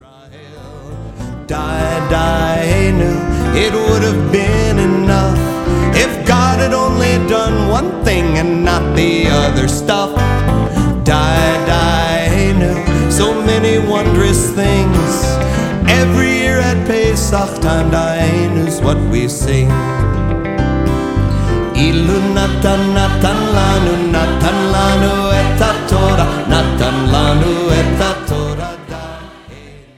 recorded with over 300 friends and fans!